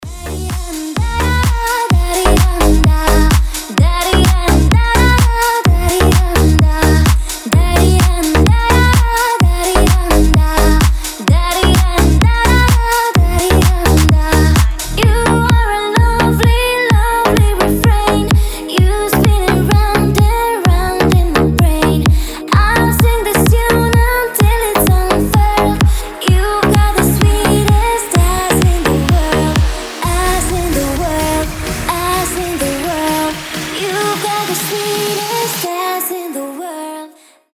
retromix
веселые
Club House
басы
Cover
slap house